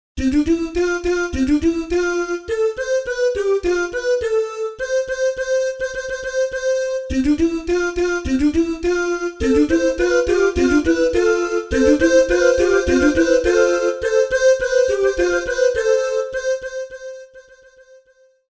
CANONS